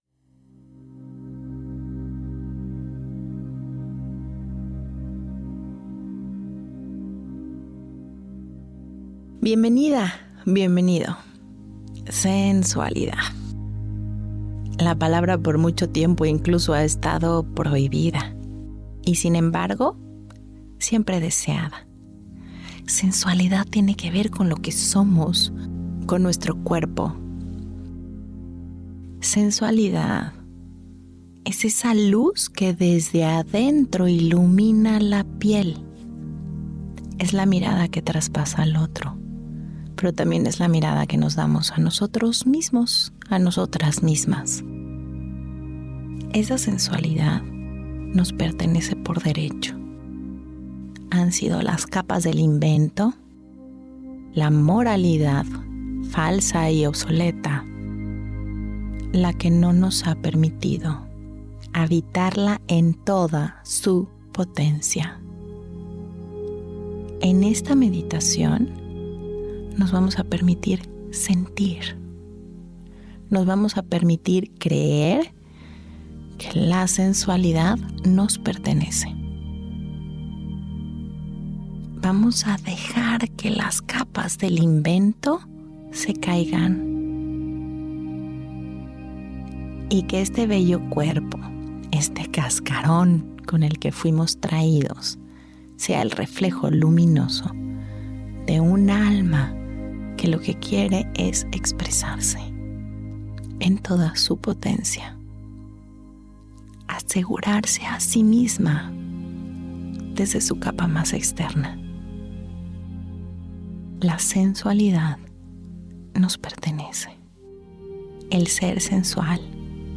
Esta meditación nos conecta con el reflejo luminoso de nuestro interior que una vez habitado, se refleja como sensualidad y seguridad en nuestro exterior.